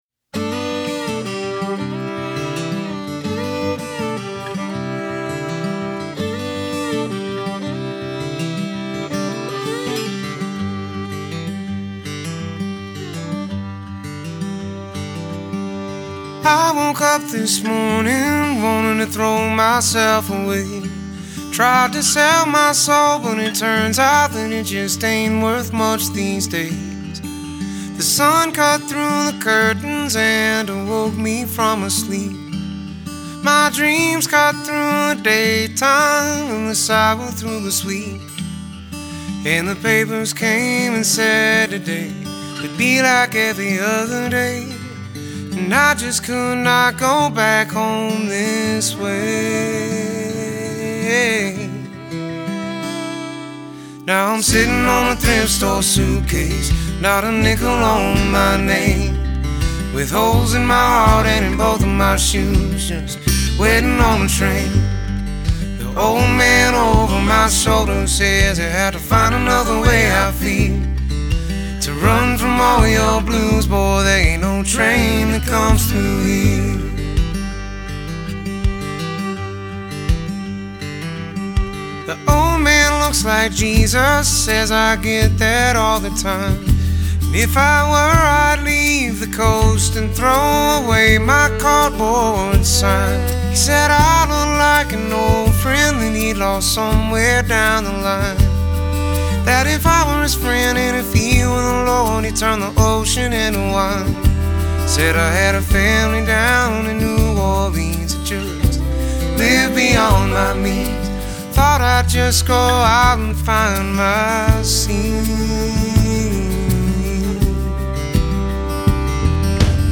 honest, sincere voice cuts to the heart
suitcase bass